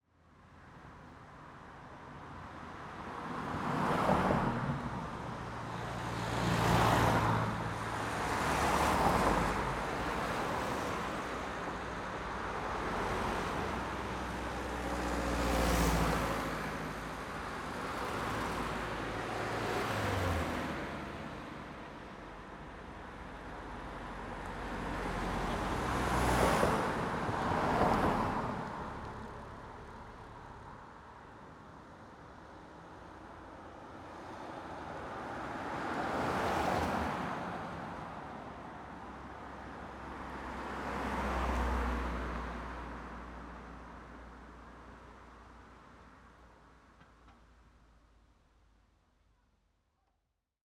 session d'enregistrements de volets. si, si.
Strasbourg - KM140 / KM120 / SXR4+
passby-autos.mp3